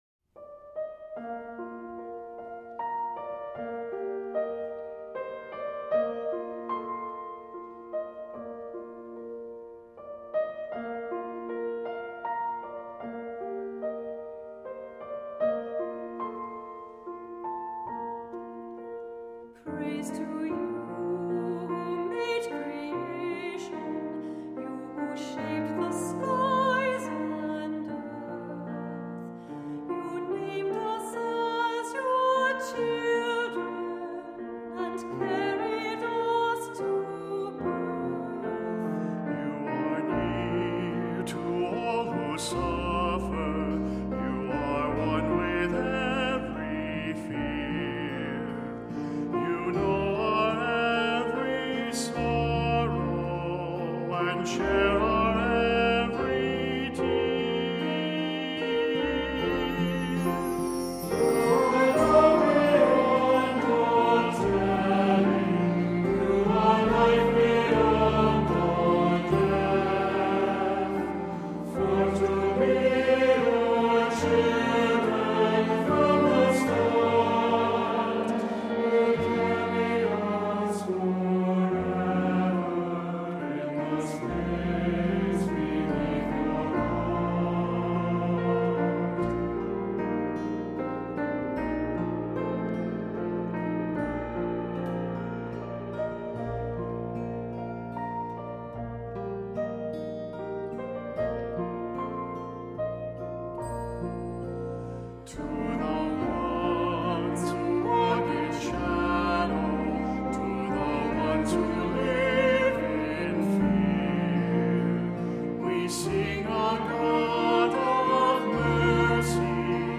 Accompaniment:      Keyboard
Music Category:      Christian
English horn or cello.Ê